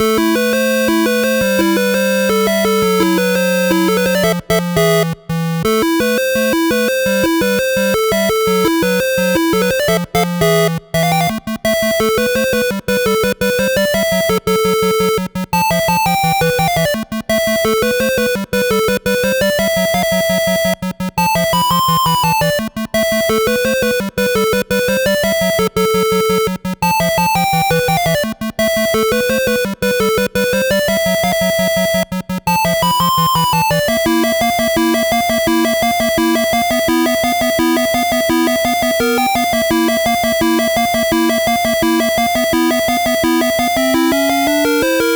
8bit（レトロゲーム風）音源
【イメージ】戦闘 など